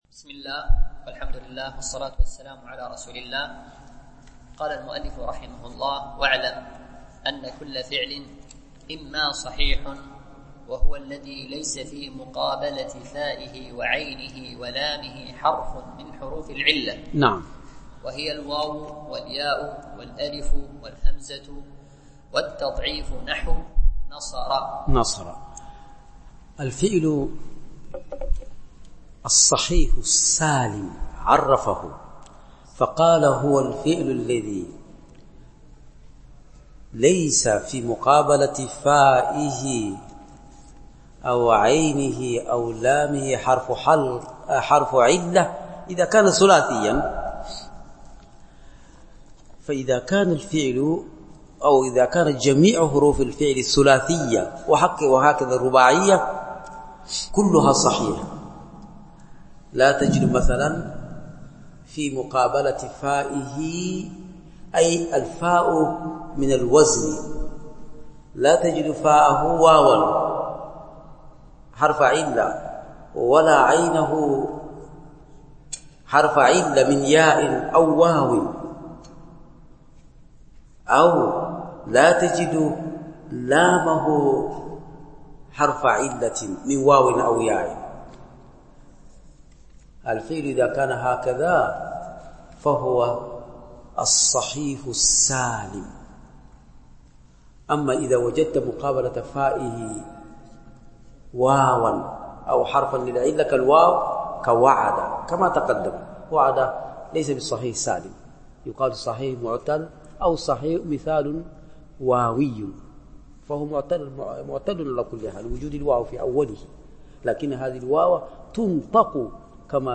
دورة علمية
بمسجد عائشة أم المؤمنين - دبي